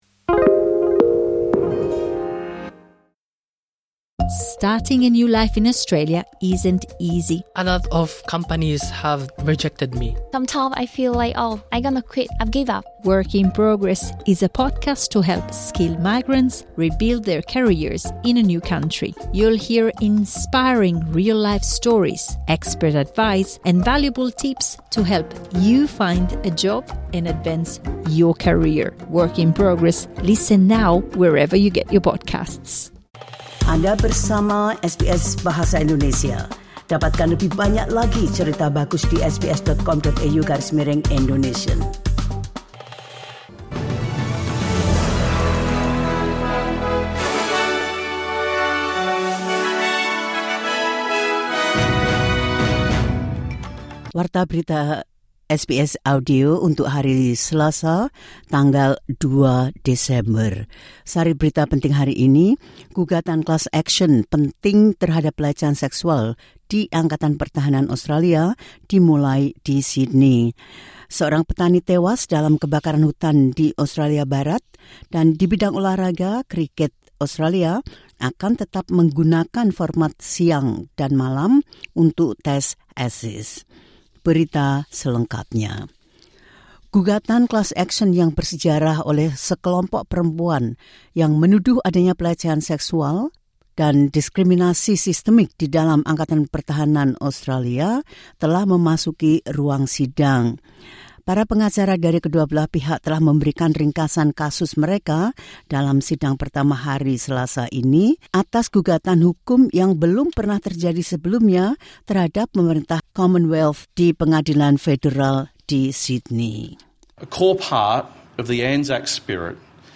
The latest news SBS Audio Indonesian Program – 02 December 2025